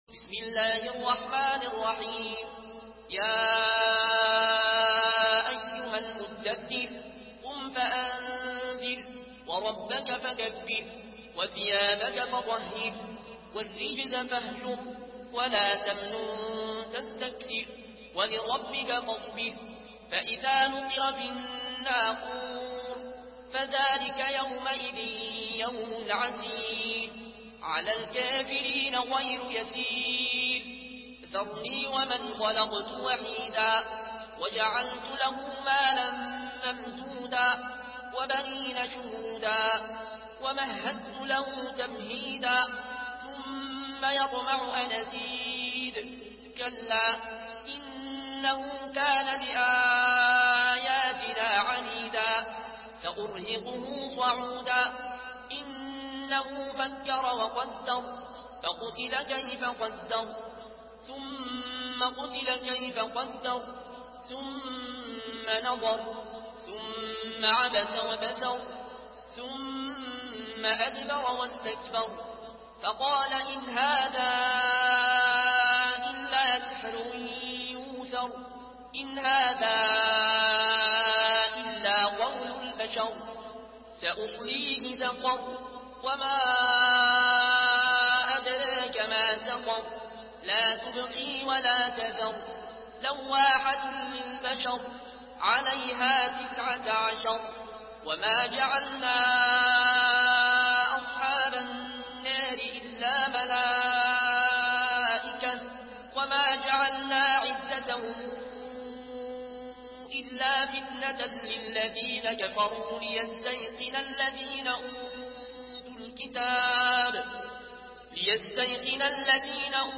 Surah আল-মুদ্দাস্‌সির MP3 by Al Ayoune Al Koshi in Warsh An Nafi From Al-Azraq way narration.